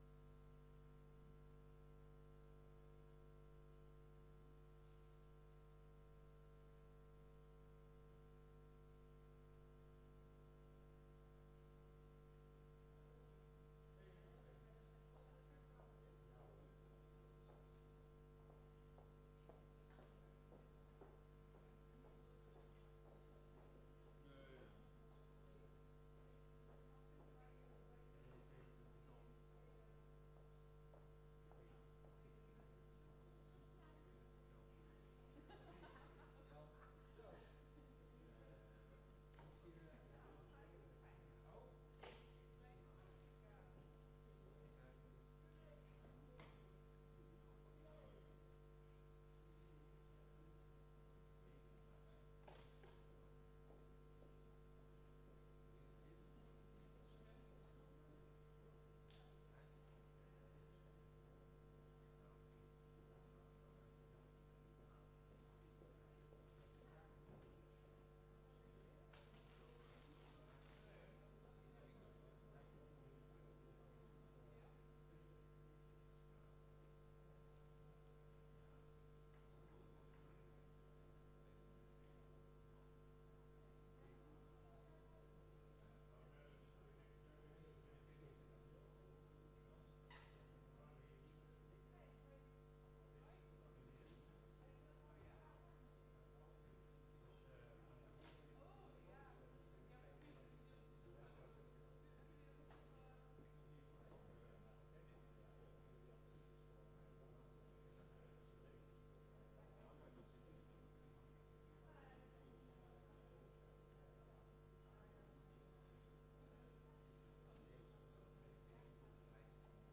Locatie: Statenzaal